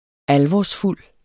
Udtale [ ˈalvɒs- ]